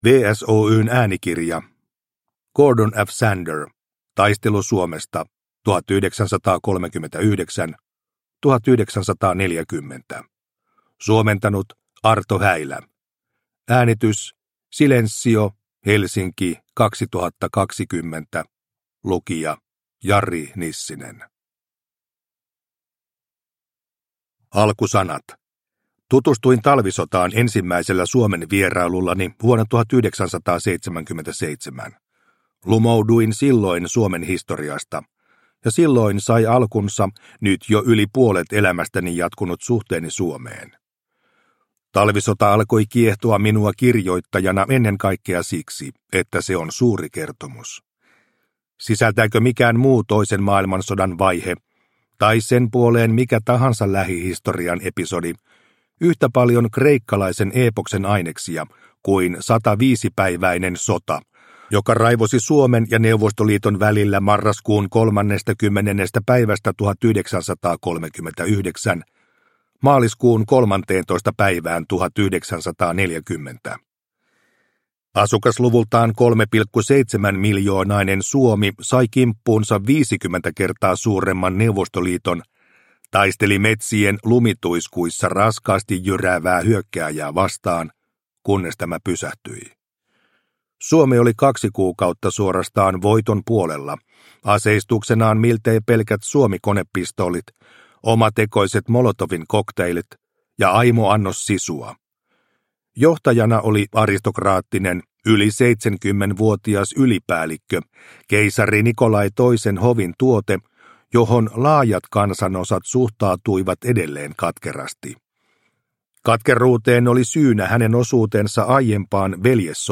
Taistelu Suomesta 1939-1940 – Ljudbok – Laddas ner